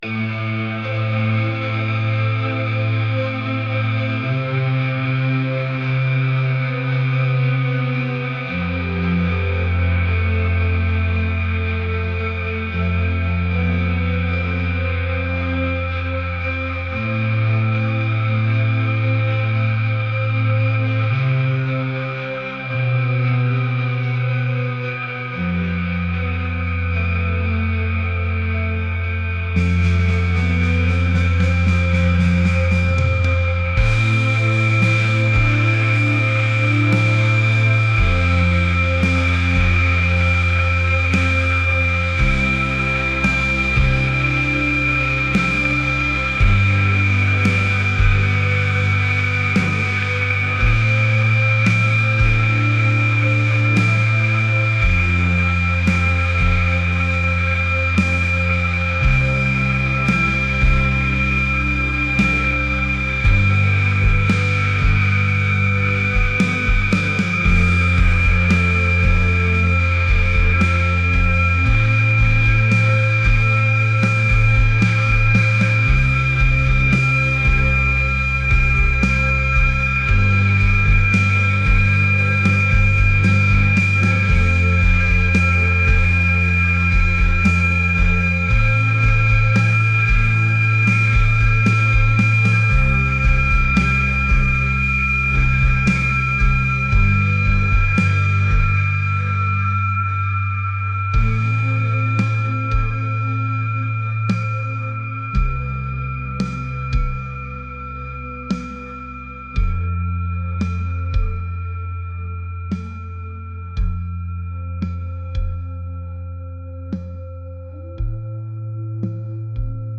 dreamy | atmospheric